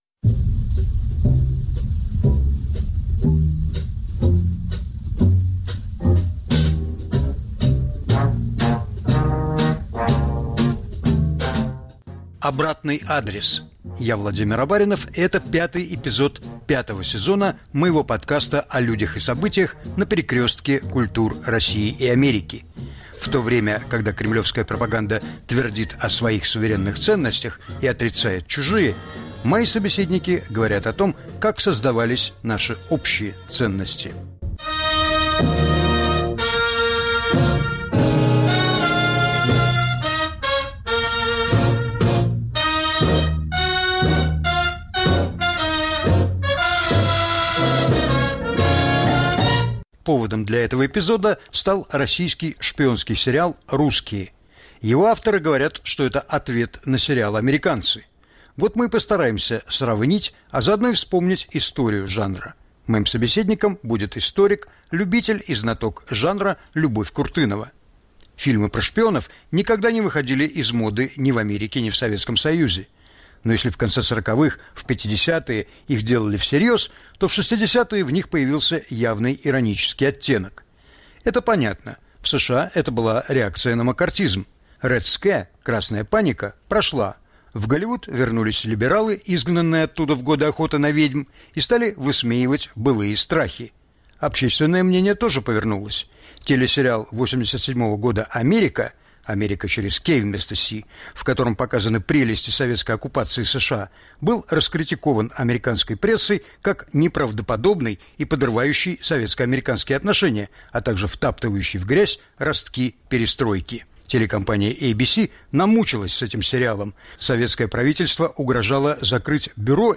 Повтор эфира от 07 апреля 2023 года.